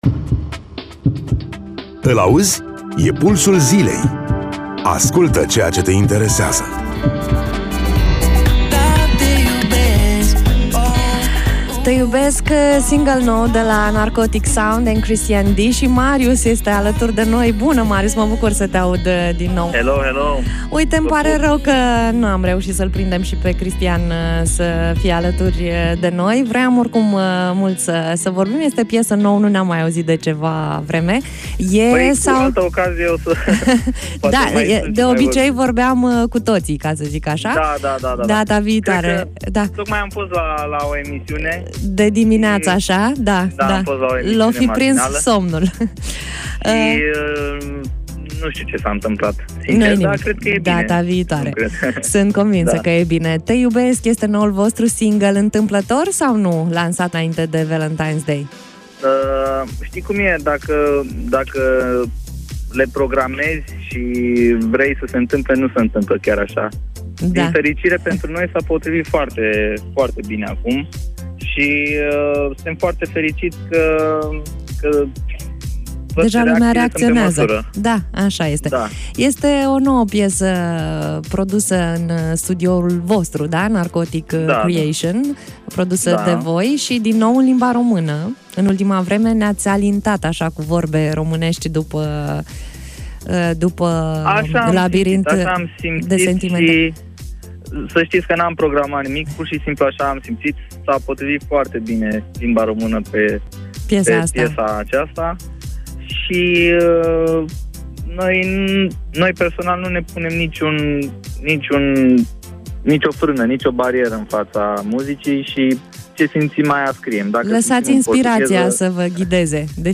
Interviu-Narcotic-Sound.mp3